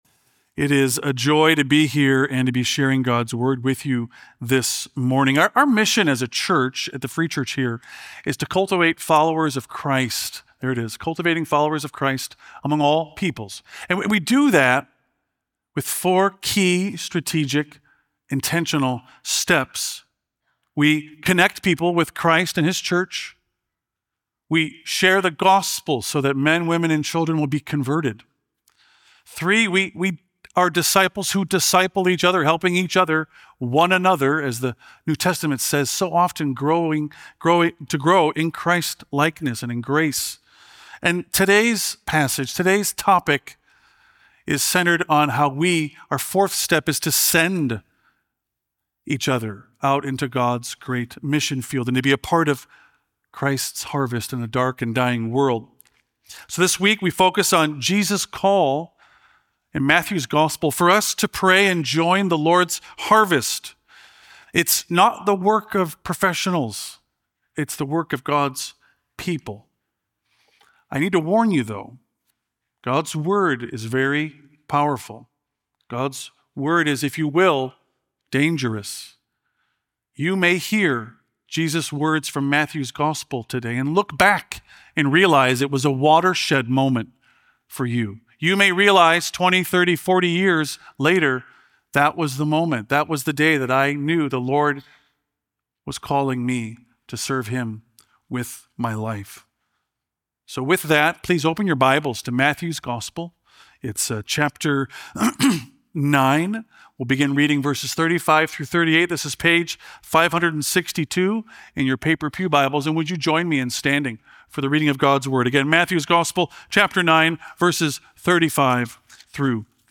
Our last sermon about our Mission and Vision